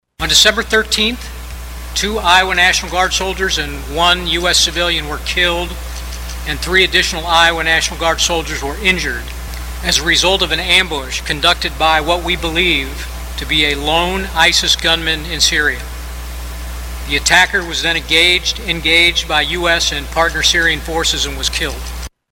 Iowa Governor Kim Reynolds and Iowa National Guard Major General Stephen Osborn held a joint news conference following the ISIS ambush attack in Syria that killed two Iowa soldiers and left three others wounded.
The news conference was held Saturday night.